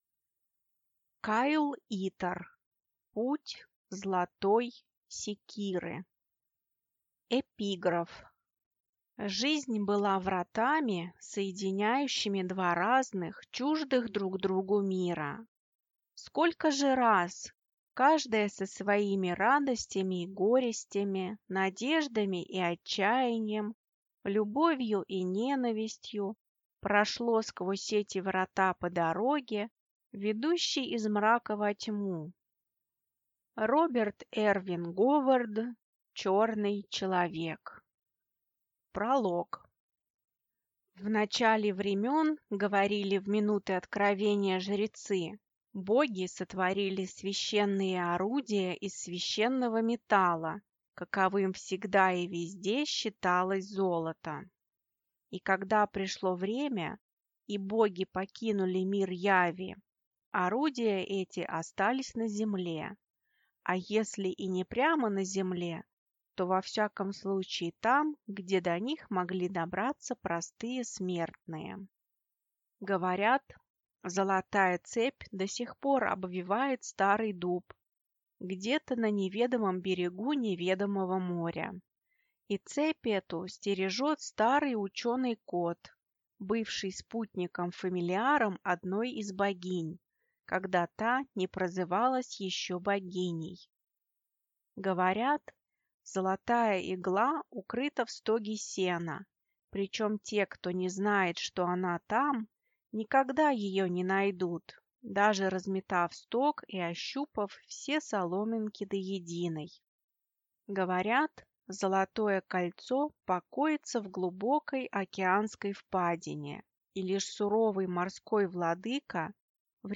Аудиокнига Путь златой секиры | Библиотека аудиокниг